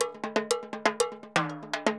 Timbaleta_Salsa 120_5.wav